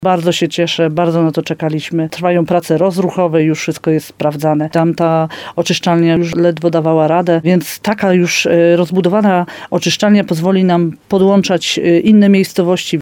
– Inwestycja dotyczyła dwóch budynków w Hańczowej i Uściu Gorlickim – mówi wójt Ewa Garbowska-Góra.